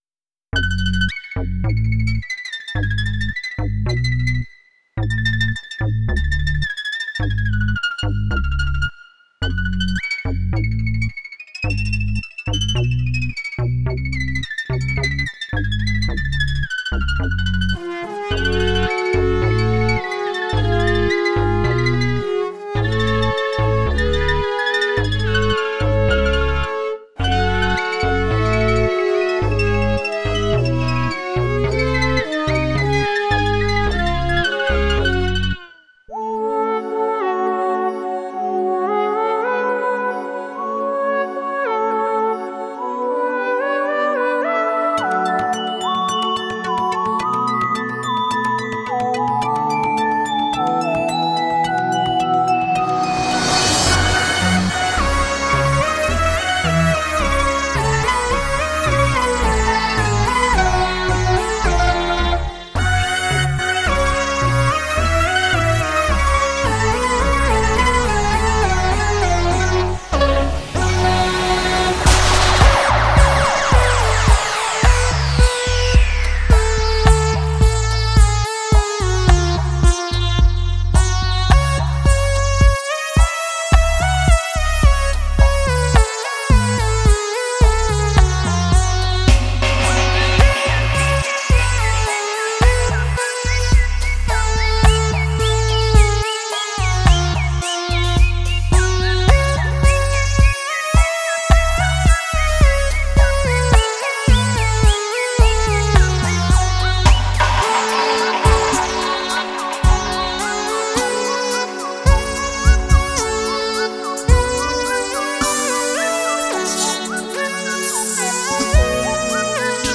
ピアニストの友達に、俺からは絶対出てこない音の動き、って言われて嬉しかった。